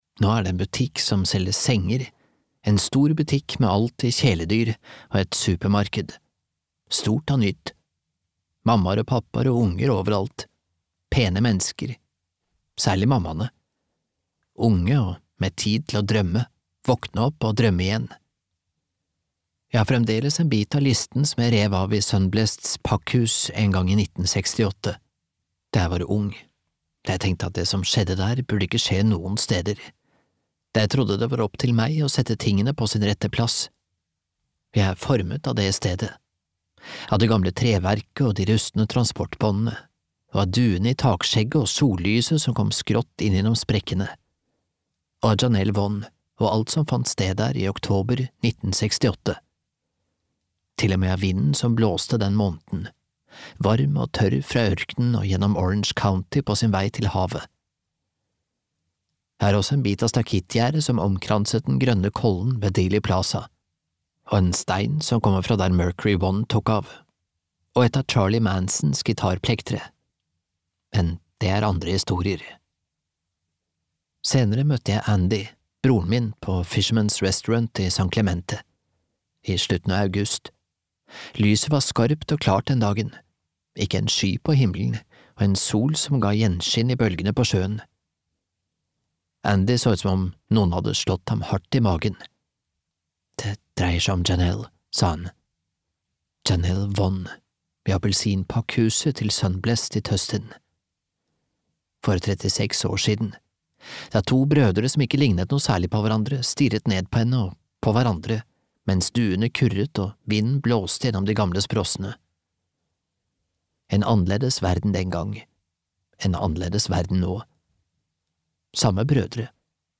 Ingen vakker død (lydbok) av T. Jefferson Parker